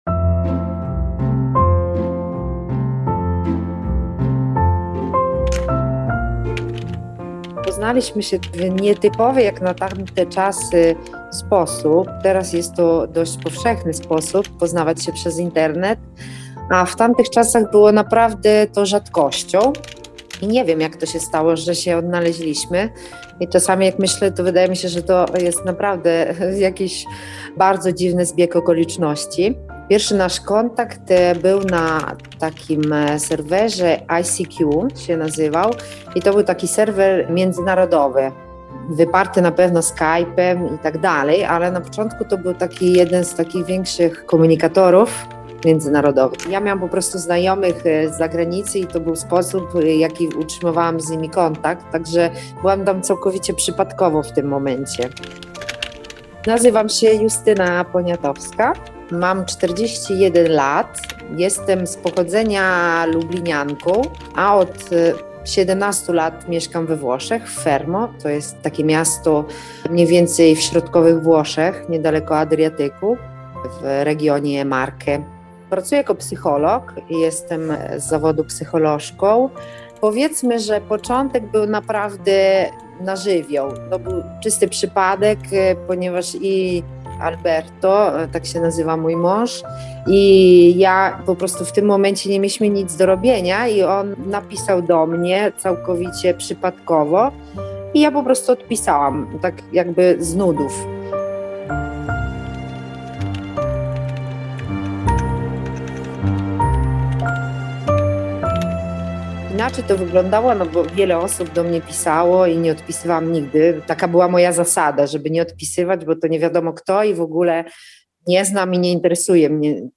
Reportaż o tym, jak znajdujemy miłość.